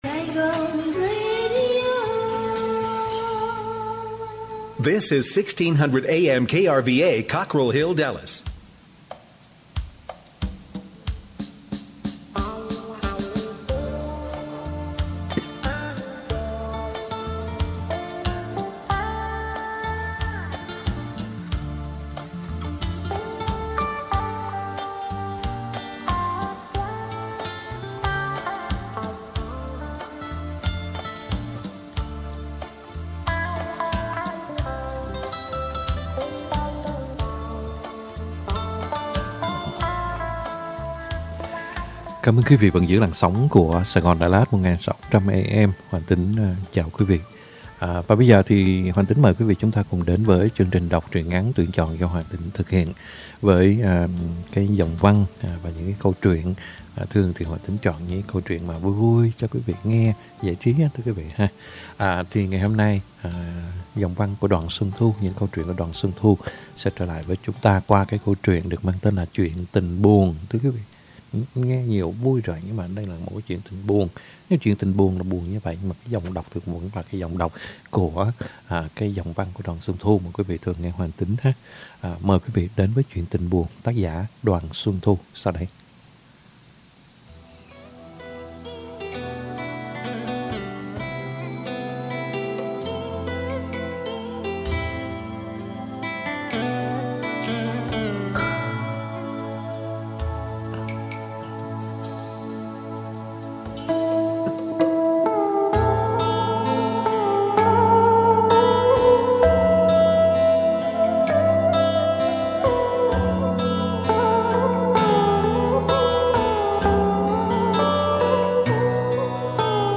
Home Đọc truyện ngắn - Chuyện Tình Buồn - 05/24/2022 .